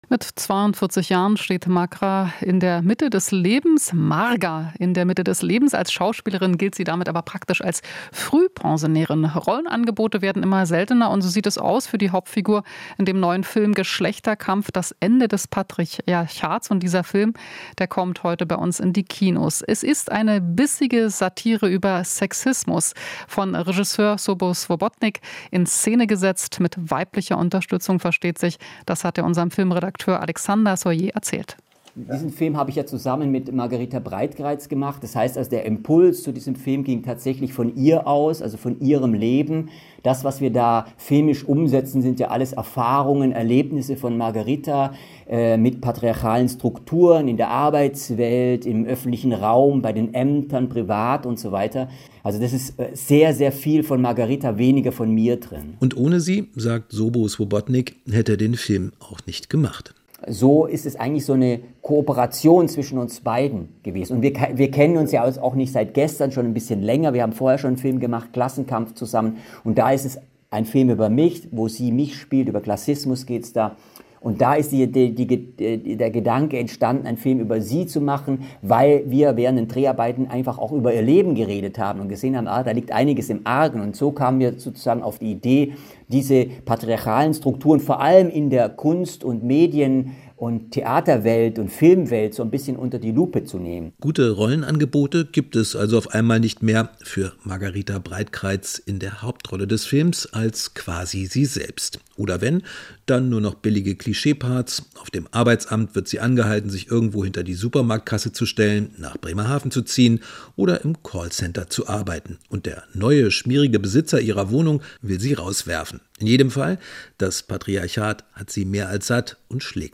Kultur - Kino-Gespräch